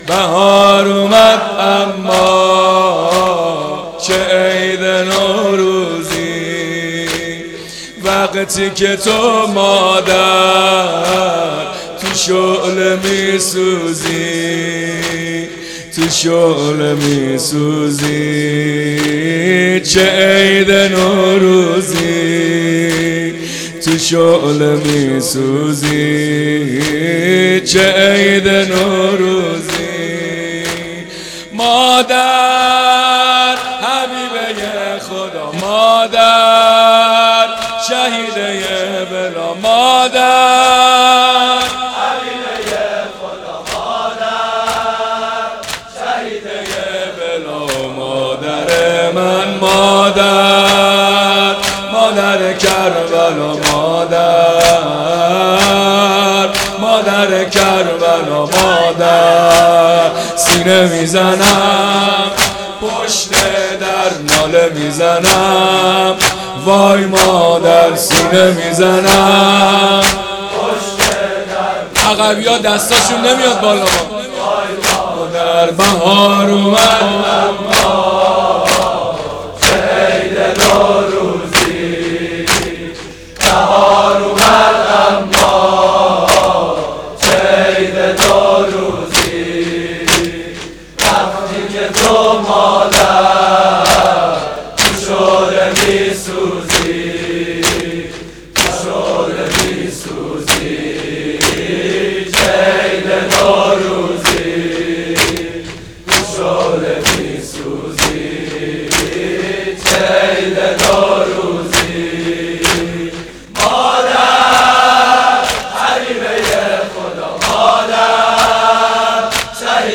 زمزمه ایام فاطمیه